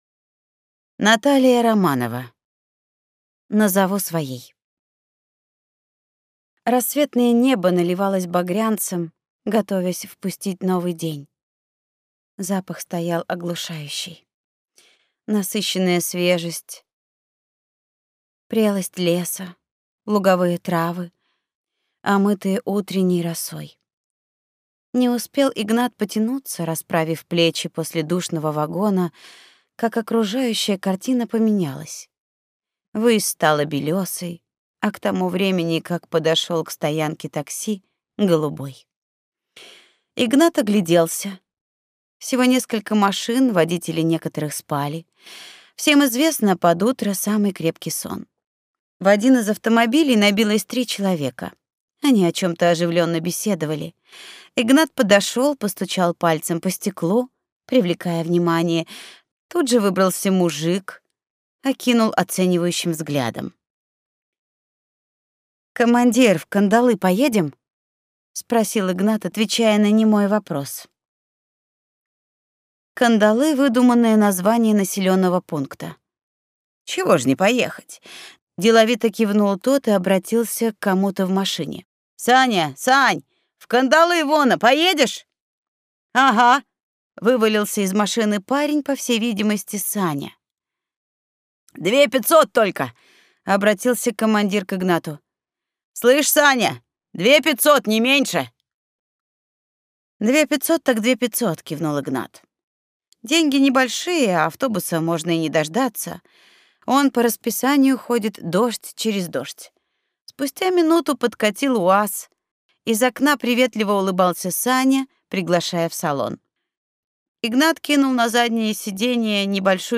Аудиокнига Назову своей | Библиотека аудиокниг